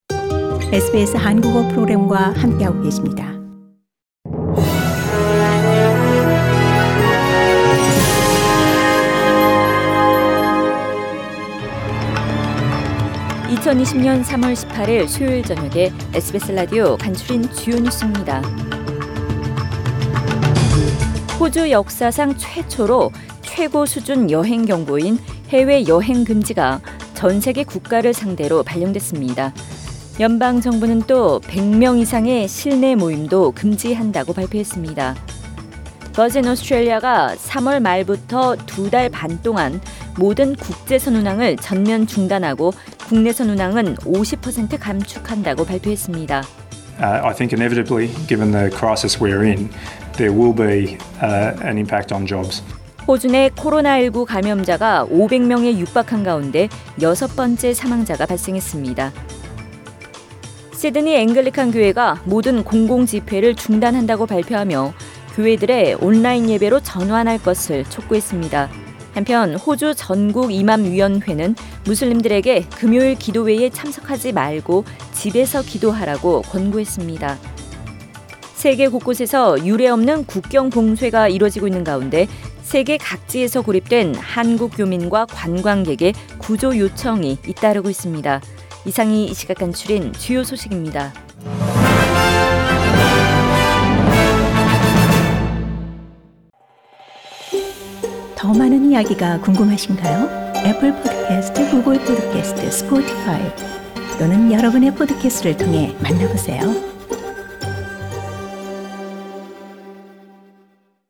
Find out Today’s top news stories on SBS Radio Korean.